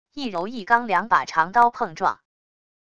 一柔一刚两把长刀碰撞wav音频